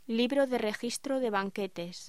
Locución: Libro de registro de banquetes
voz